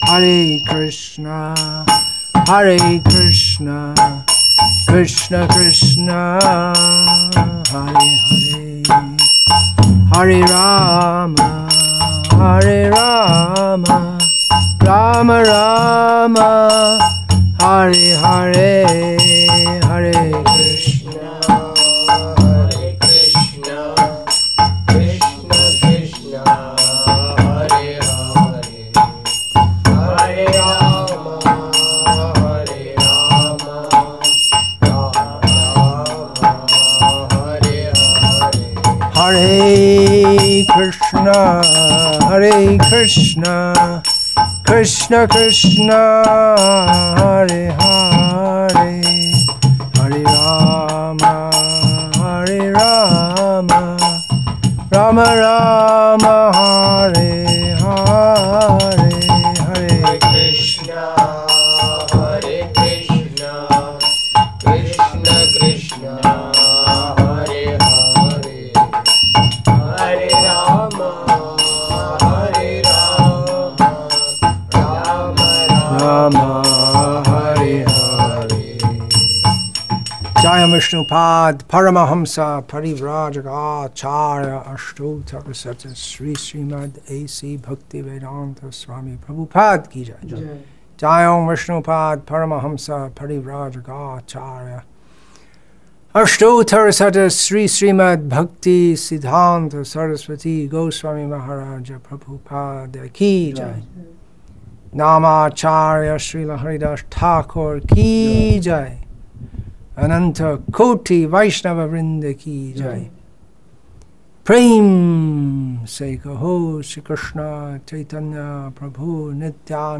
Vyasa-puja Lecture